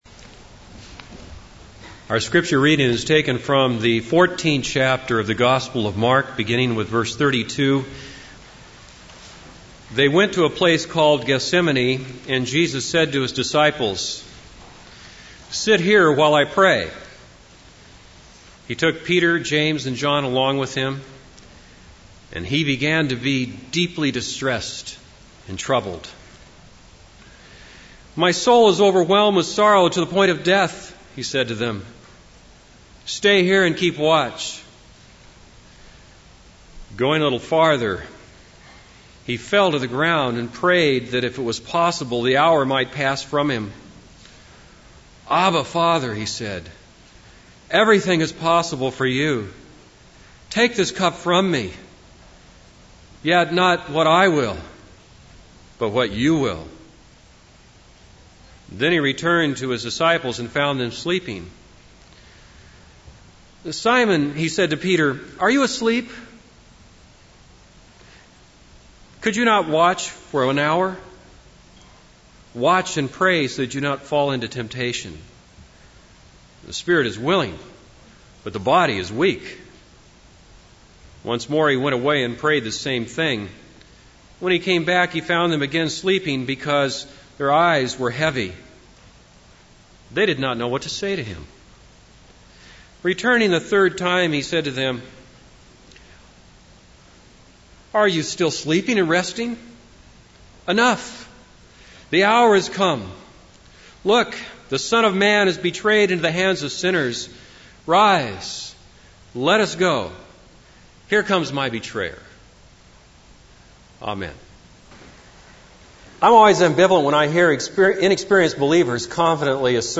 This is a sermon on Mark 14:32-42.